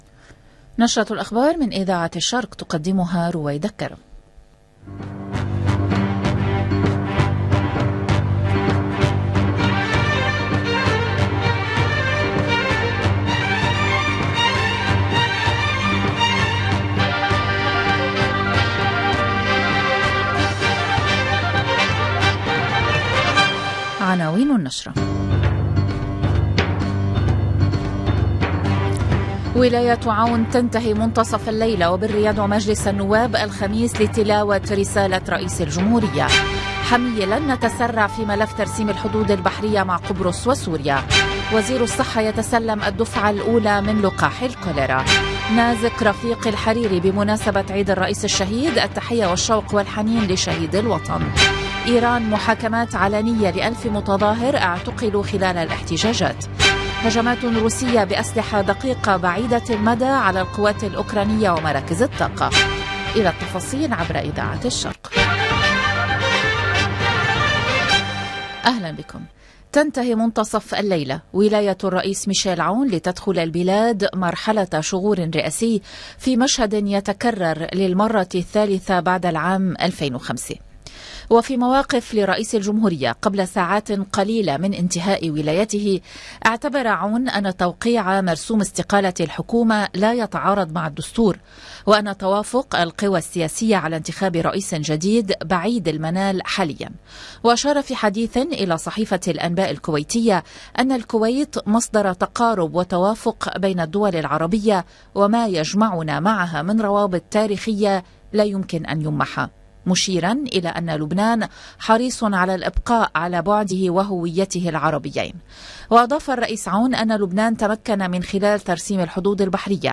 LE JOURNAL DU SOIR DU LIBAN DU 31/10/2022
EDITION DU JOURNAL DU LIBAN DU SOIR DU 31/10/2022